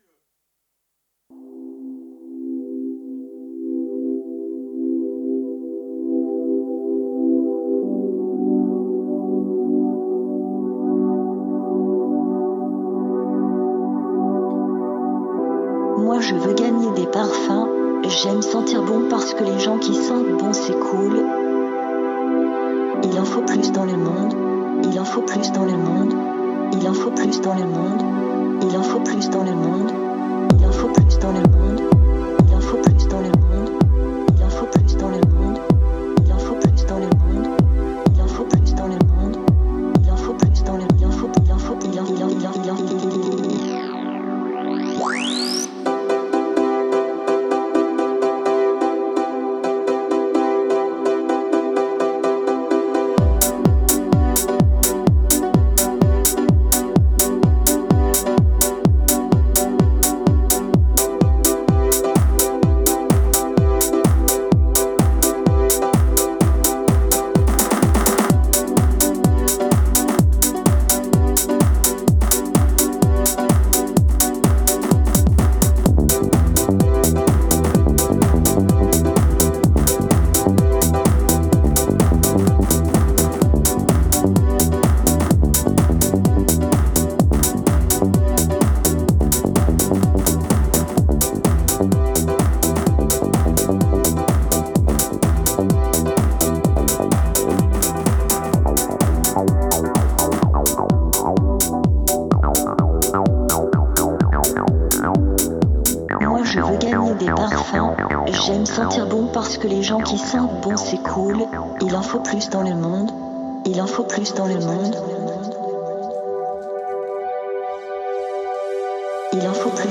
a assuré la technique en public
la retransmission en direct sur nos ondes
un DJ set
pour terminer la soirée en douceur et en énergie sur la scène